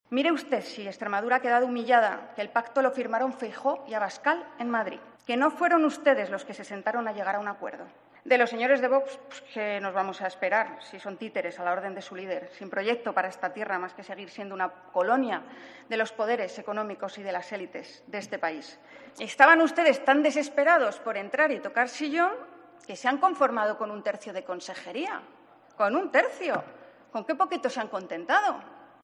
Intervención en el debate de Irene de Miguel